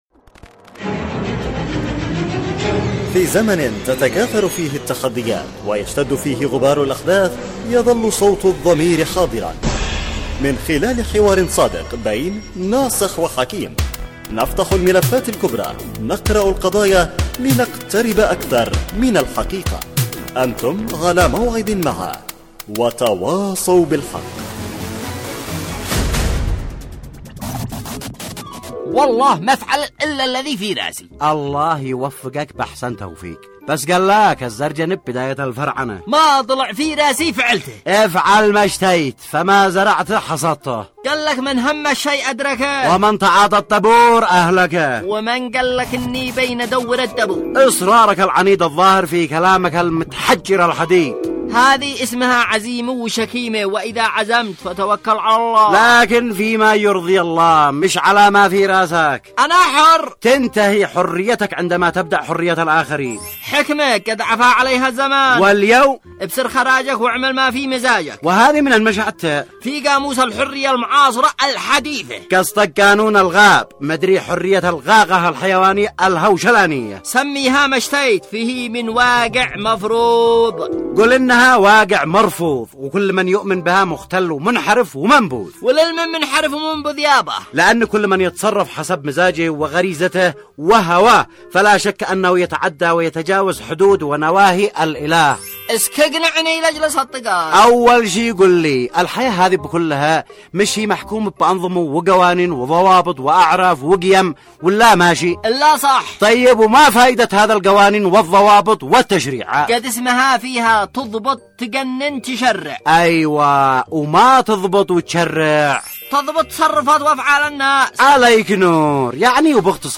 وتواصوا بالحق، برنامج إذاعي درامي يعمل كل يوم على طرح إشكالية و مناقشة مشكلة تهم الجميع وبعد جدال بين الطرفين يتم الاحتكام بينهم الى العودة الى مقطوعة للسيد القائد تعالج المشكلة